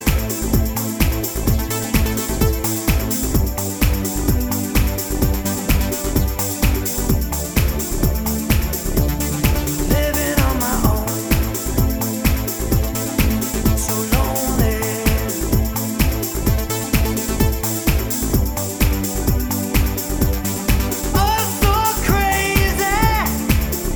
Single Version Pop (1980s) 3:28 Buy £1.50